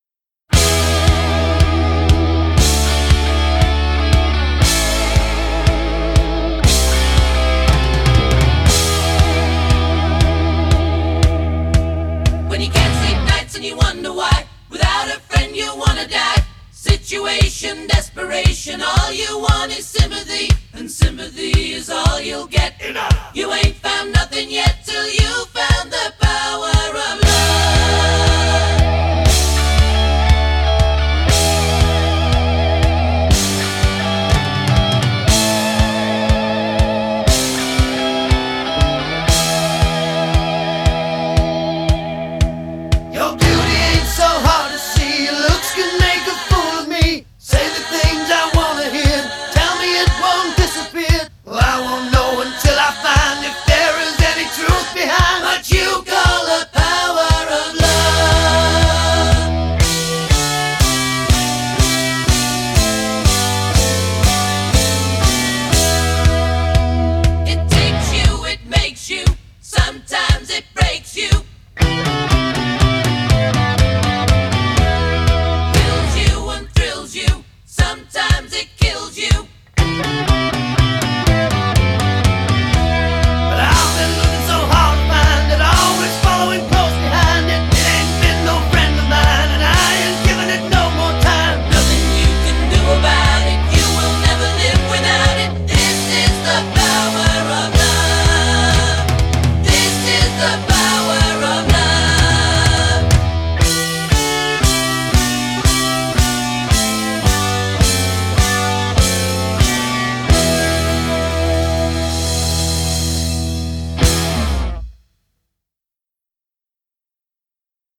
Genre: Soft Rock